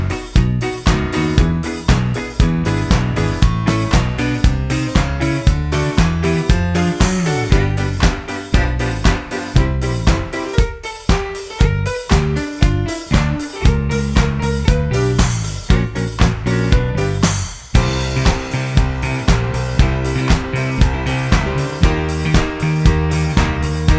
for Duet Rock 'n' Roll 4:22 Buy £1.50